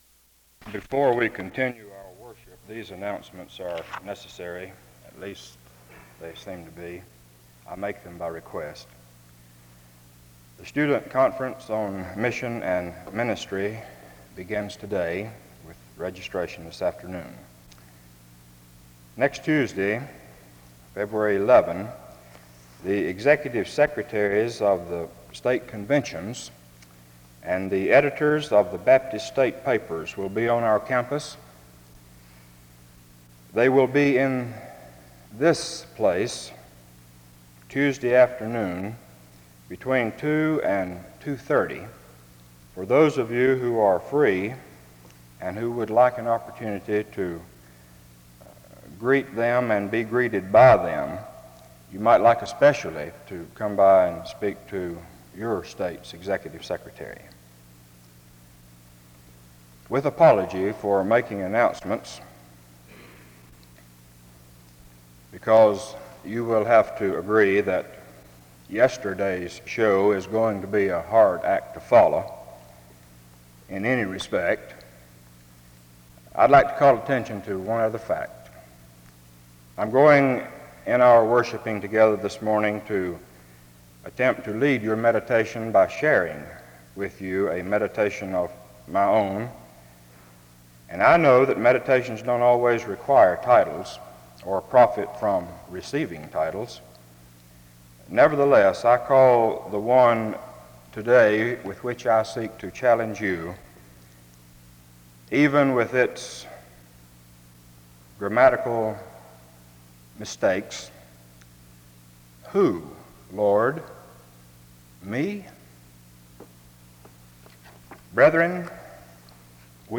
A responsive reading takes place from 2:20-4:15. A prayer is offered from 4:20-5:49.